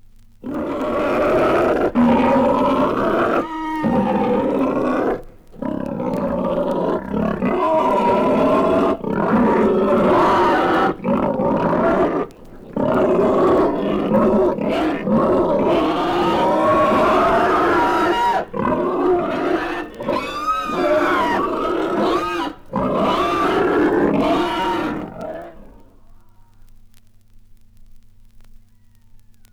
• monster roaring and screaming.wav
mosnter_roaring_and_screaming_tOr.wav